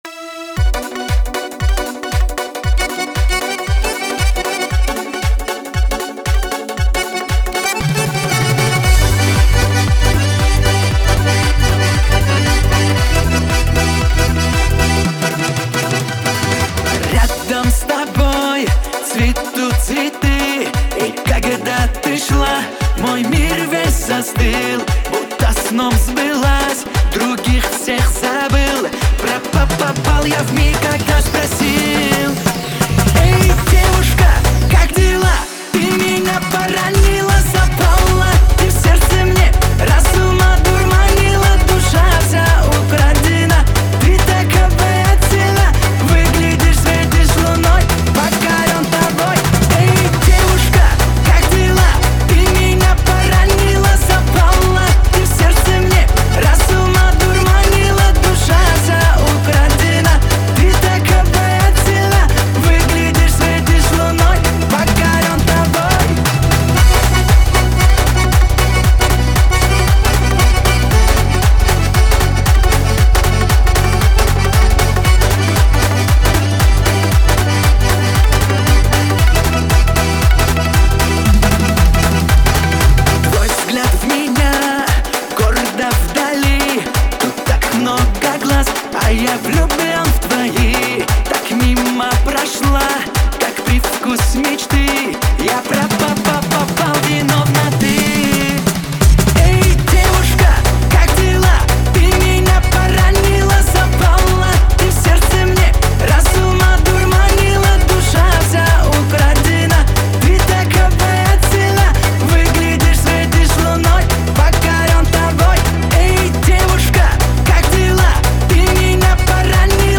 Плейлисты: Кавказские песни 2025
Ремиксы